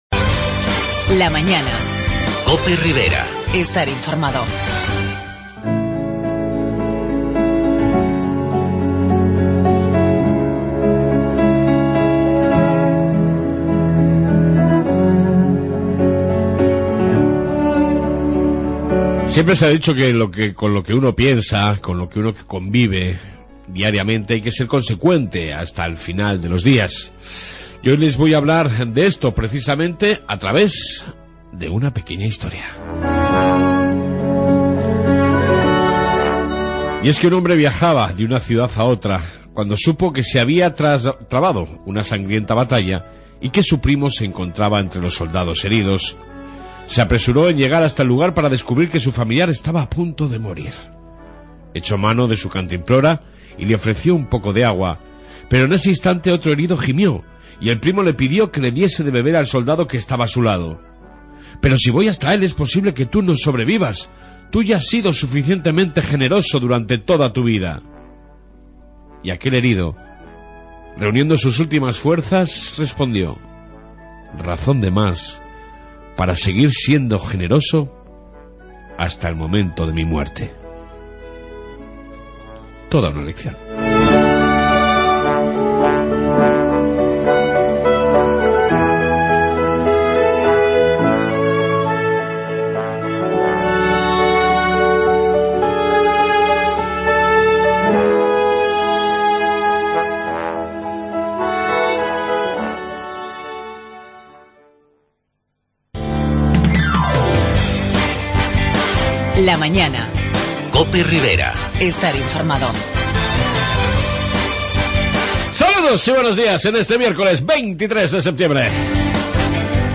AUDIO: En esta 1 parte Reflexión matutina, Informe Policia municipal y amplia entrevista con la lider del Pp en tudela Irene Royo